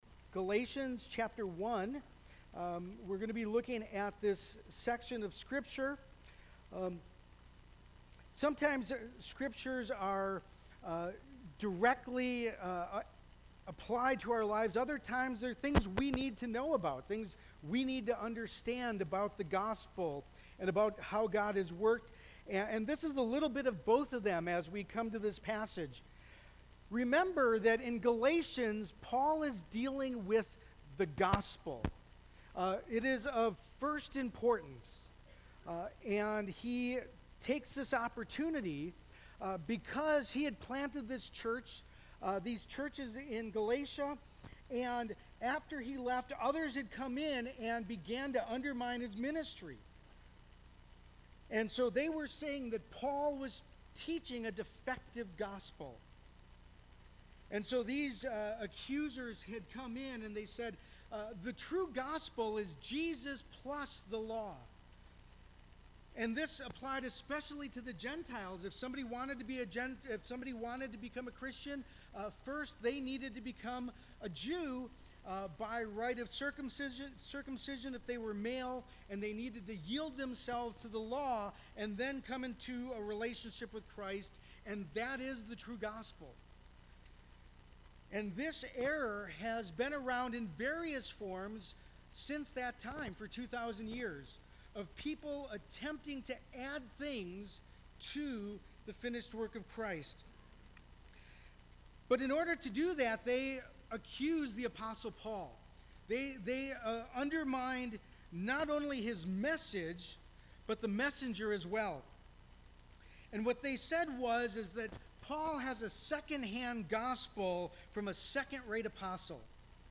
Service: Sunday Morning